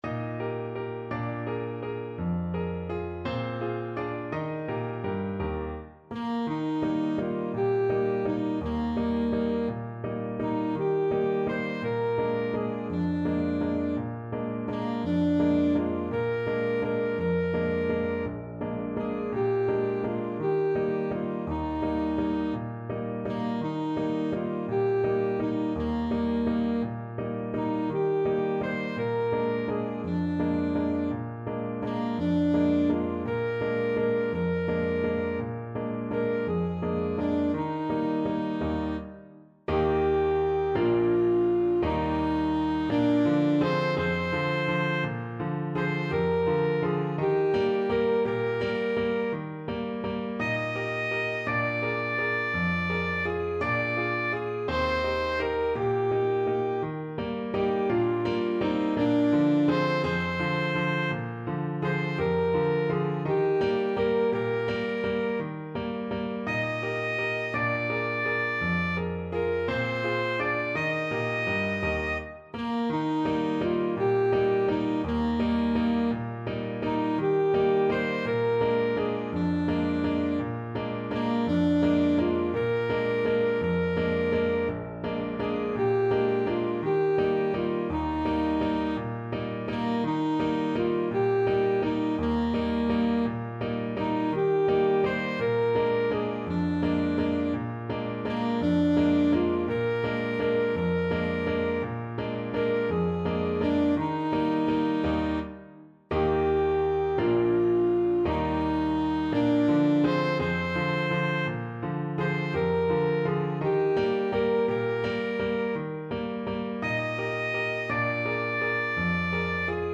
Alto Saxophone version
3/4 (View more 3/4 Music)
One in a bar =c.168
Traditional (View more Traditional Saxophone Music)